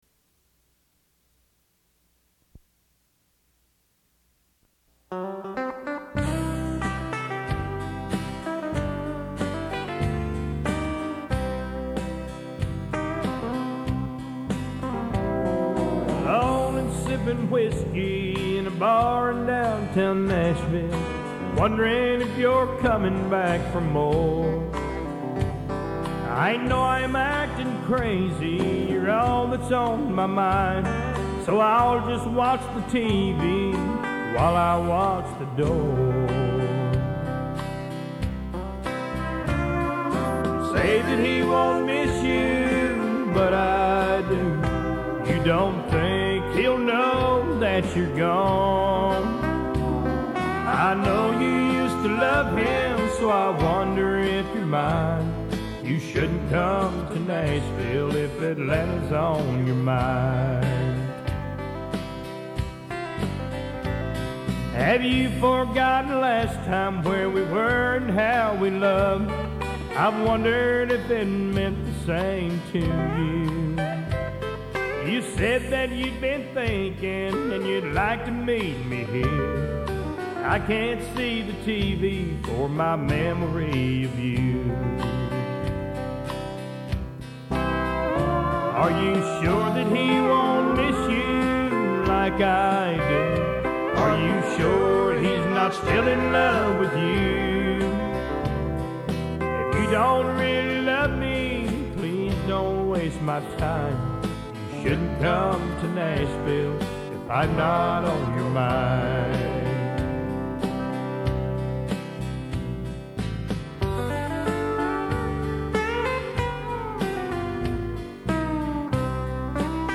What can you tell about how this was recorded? I'm surprised nobody has said this yet, but you're not going to be able to change much in these recordings since they're just stereo tracks.